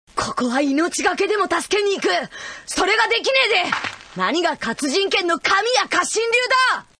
It's hard to say, since he sounds as young as he is ^_^